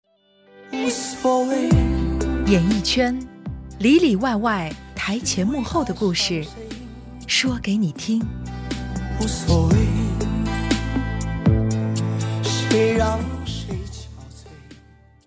Sprecherin chinesisch, Muttersprachlerin.
Sprechprobe: Werbung (Muttersprache):
Chinese female voice-over talent